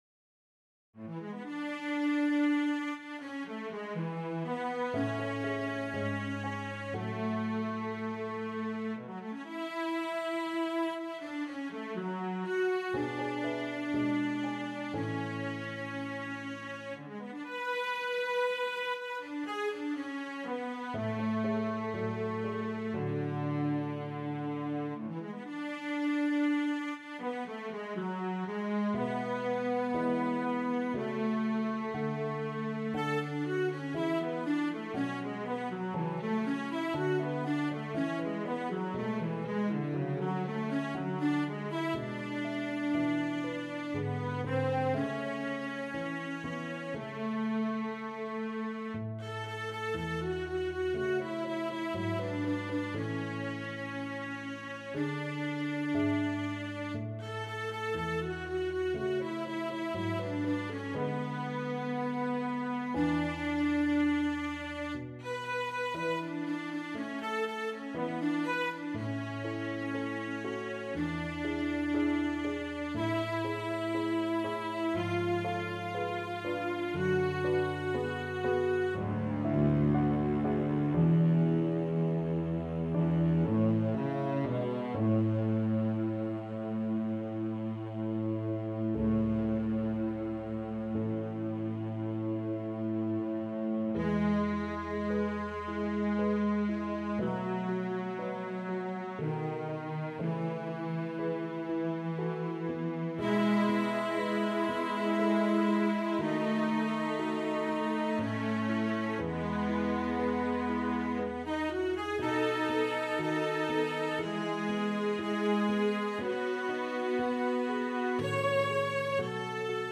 A neo-classical sonata of 3 movements for Cello and Piano